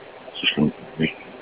Guest EVPs